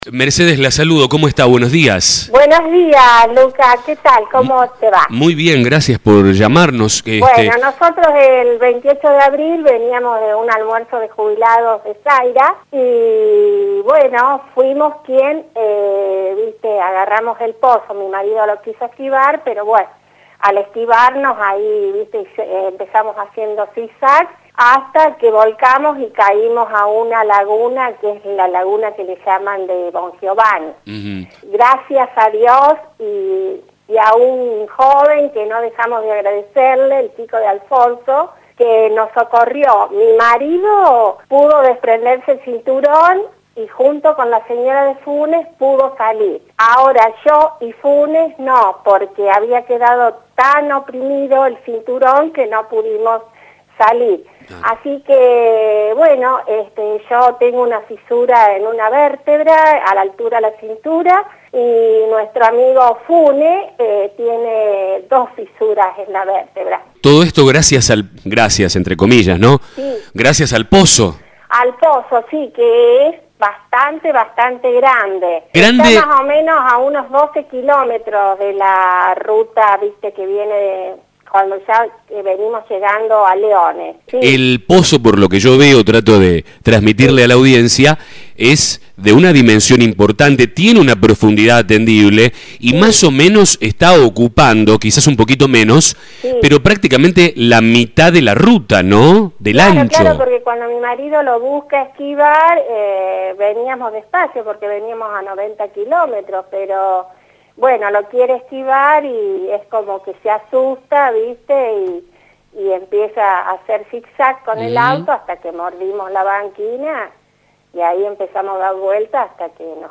Escucha a los oyentes y comentario sobre gestiones realizadas: